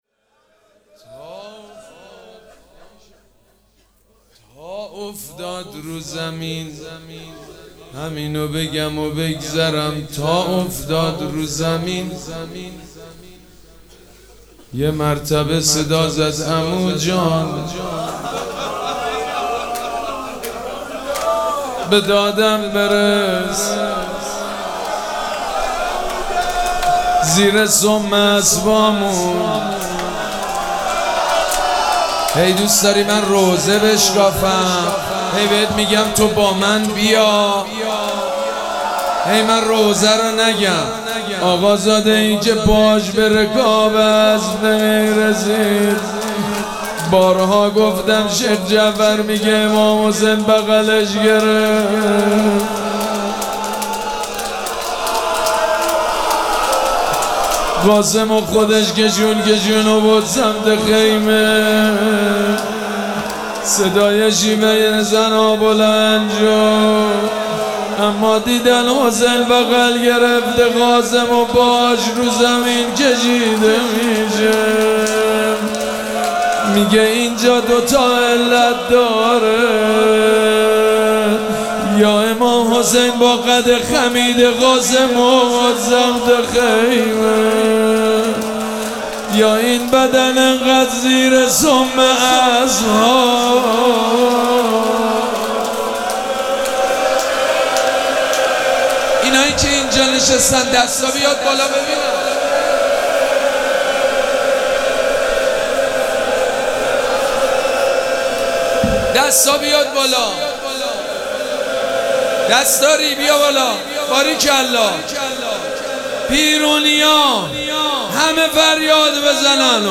مراسم عزاداری شب ششم محرم الحرام ۱۴۴۷
روضه
حاج سید مجید بنی فاطمه